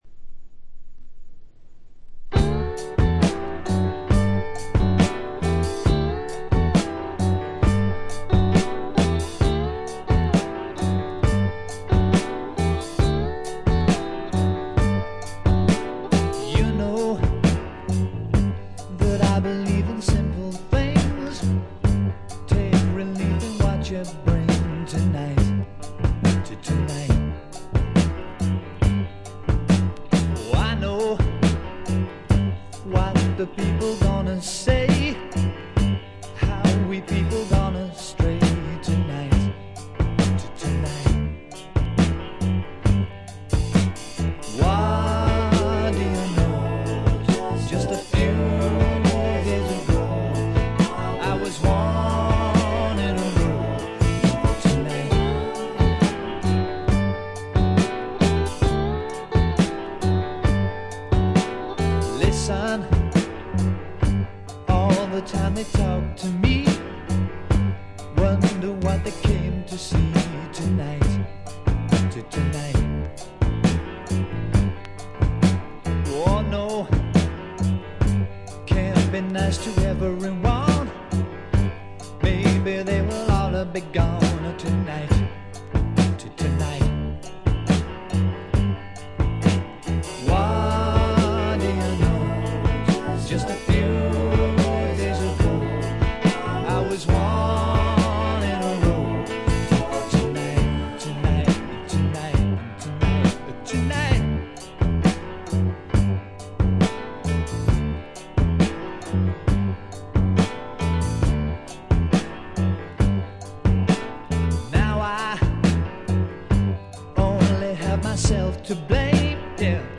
A面はほとんどノイズ感無し。
味わい深い美メロの良曲が連続する快作。
試聴曲は現品からの取り込み音源です。